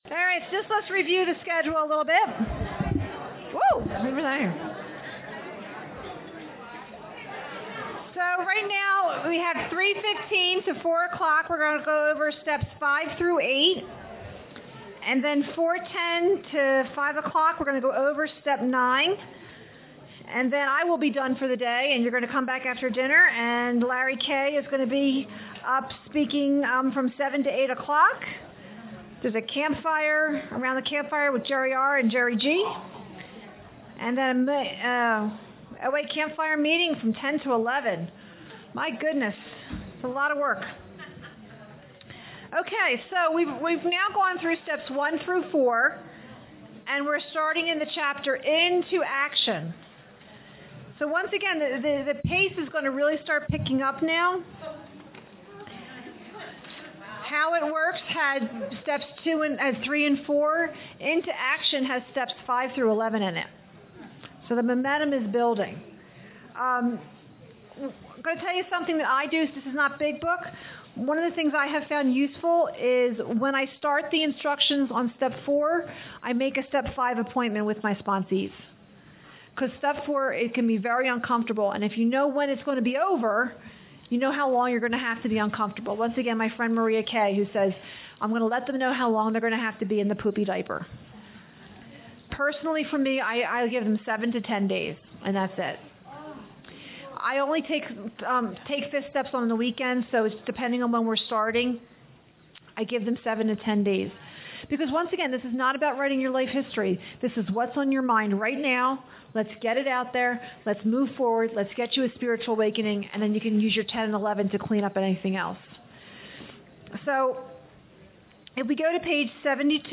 A Big Book Retreat in Estes Park